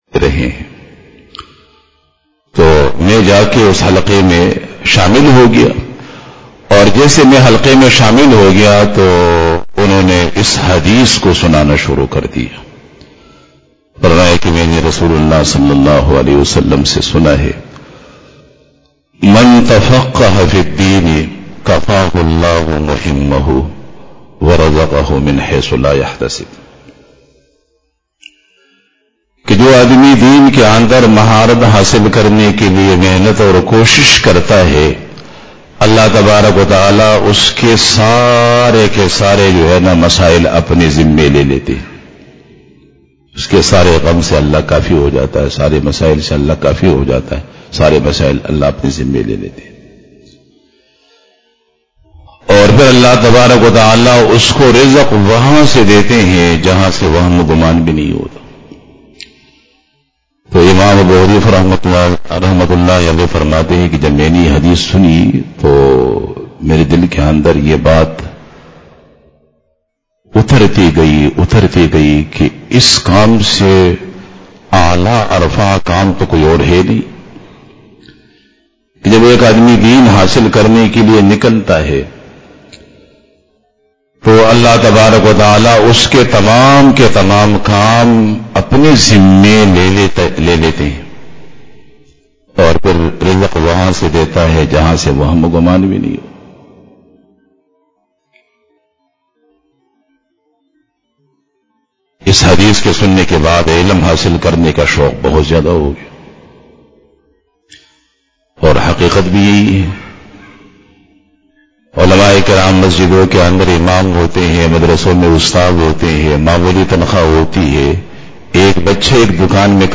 پہلا دن دورہ تفسیر القرآن الکریم ابتدائی حفلہ
Bayan